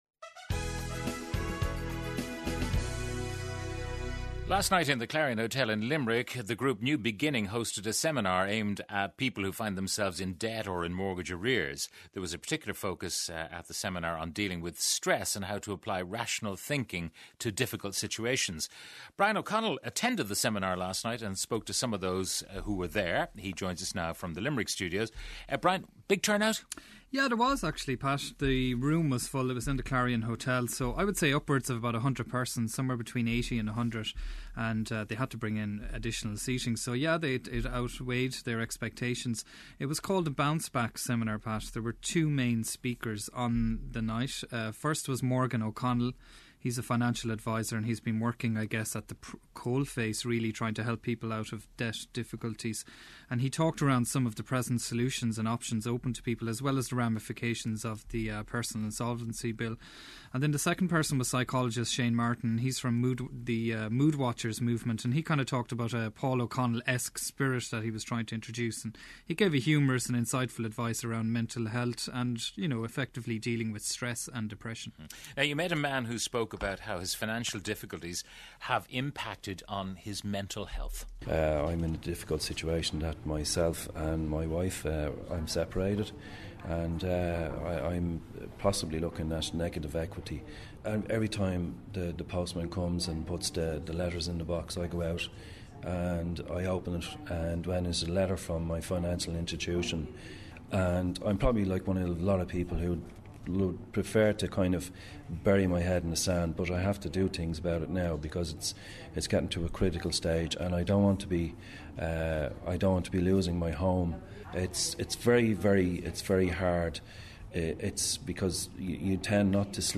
As personal and mortgage debt in Ireland soars, a new organization is hoping to alleviate some people's financial problems. Last night, in the Clarion Hotel in Limerick, the group New Beginning hosted a seminar aimed at persons who find themselves in debt or mortgage arrears. There was a particular focus at the seminar on dealing with stress and how to apply rational thinking to difficult situations. I spoke to some of those who attended.